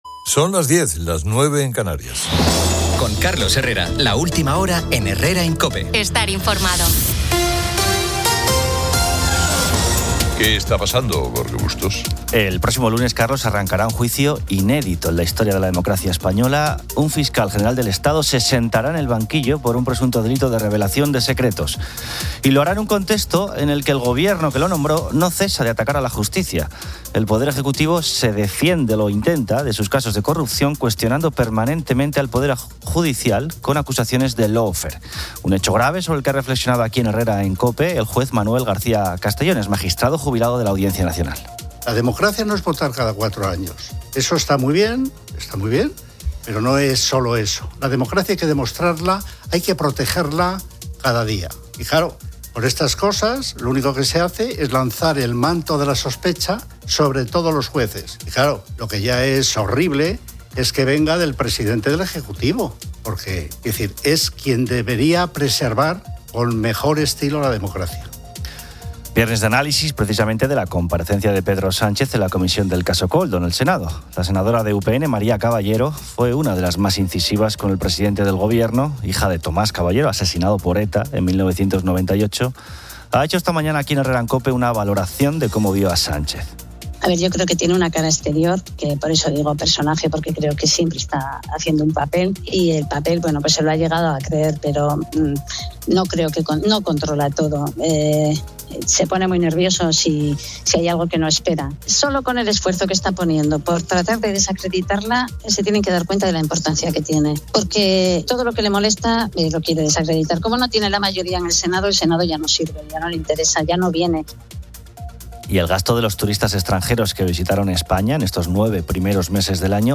Carlos Herrera dirige la última hora en COPE.